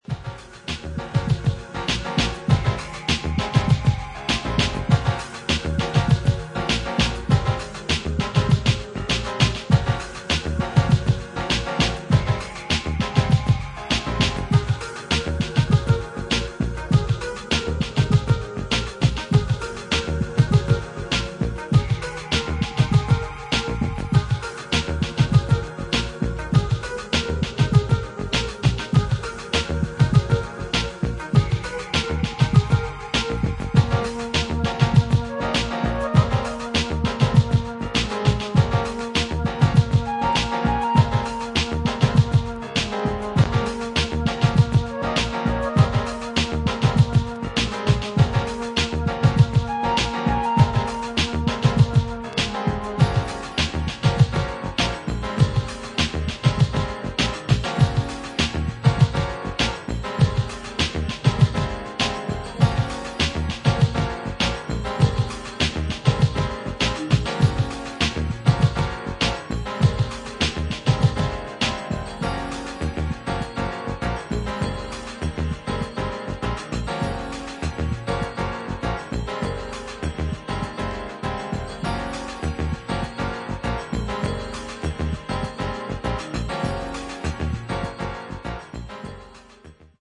コンディション：VG＋ ※薄いスリキズあり、チリノイズあり。